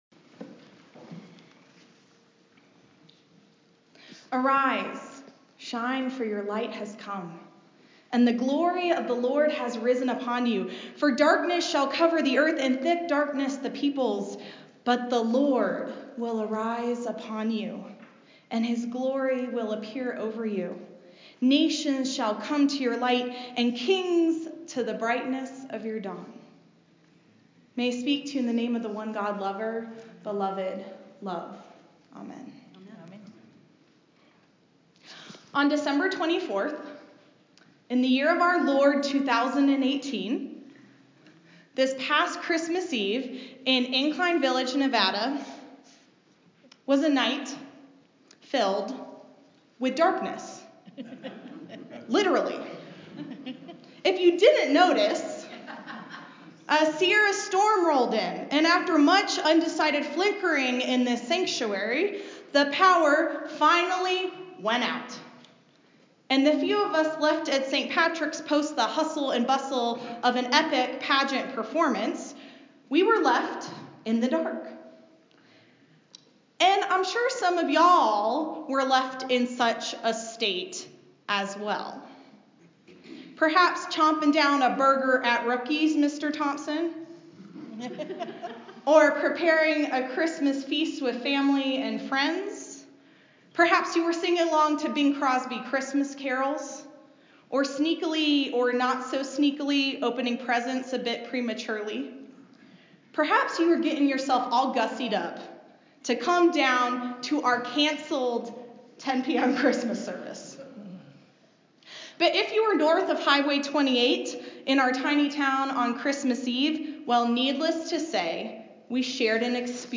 a sermon for the feast of the Epiphany